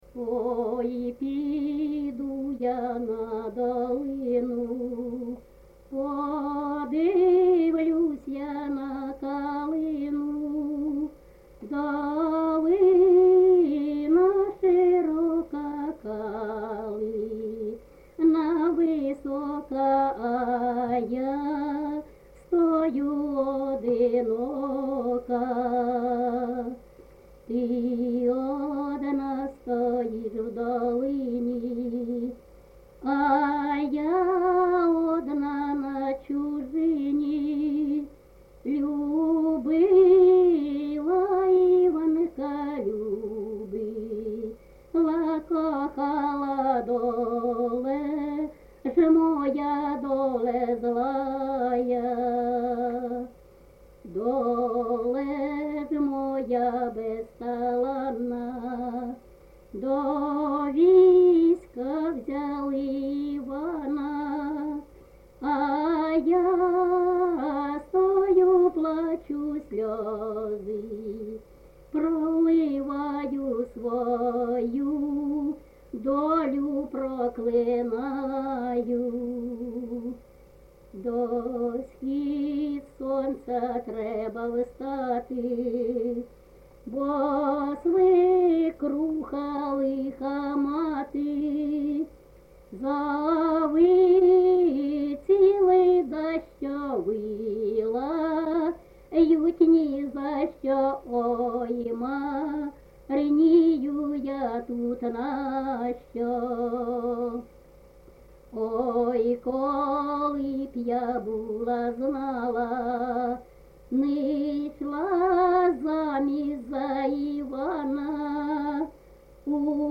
ЖанрПісні з особистого та родинного життя
Місце записус-ще Михайлівське, Сумський район, Сумська обл., Україна, Слобожанщина